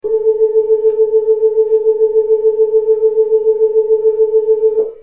To make it easy to do some signal analysis, each recording consists of only a single note, the A above middle C, with only the 8′ drawbar pulled out. This gives an almost pure 440Hz sine wave.
Original full vibrato. Notice the periods of almost zero amplitude, which result in very audible thumping.
old-vibrato.mp3